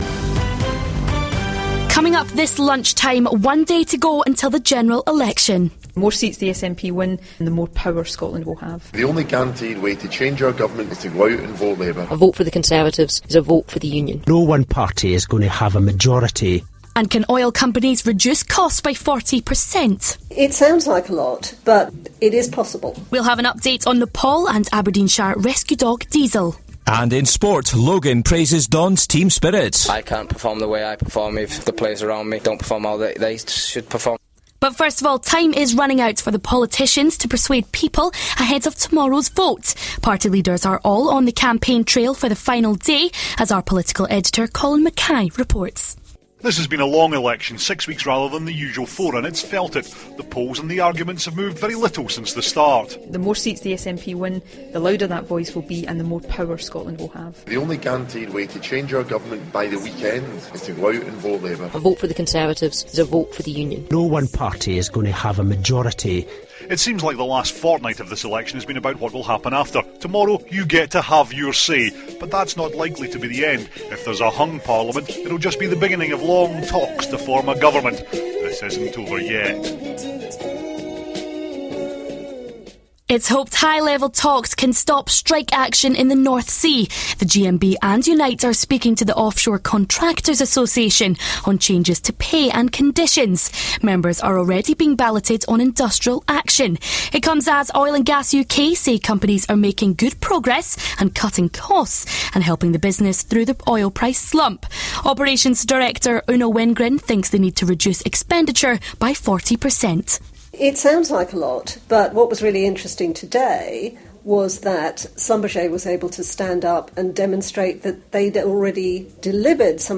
6th May 1pm Extened News Bulletin